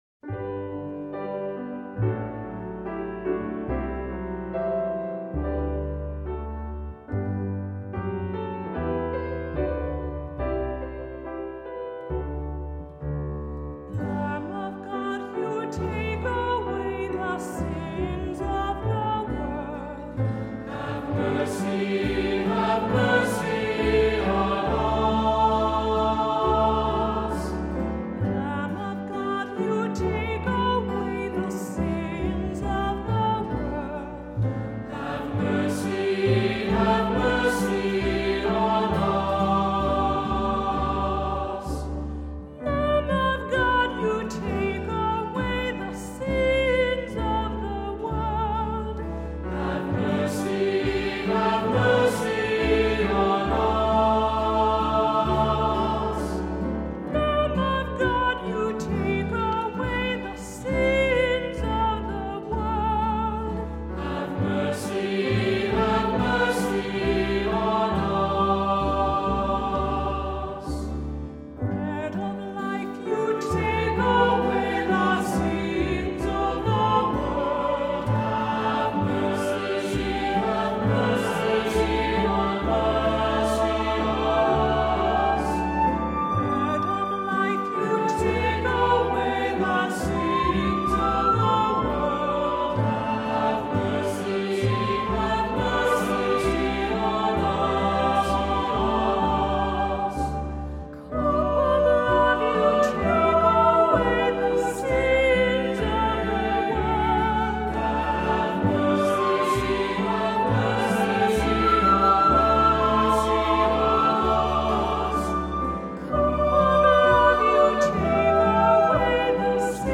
Voicing: Unison with descant; SAB; Cantor; Assembly